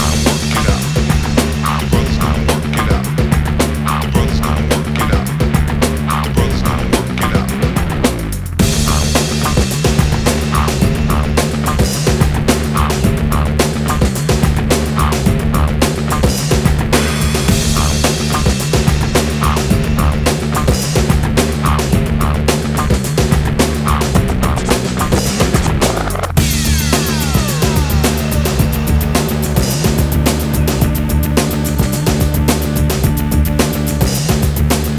Жанр: Рок / Танцевальные / Хаус / Электроника / Джаз / Техно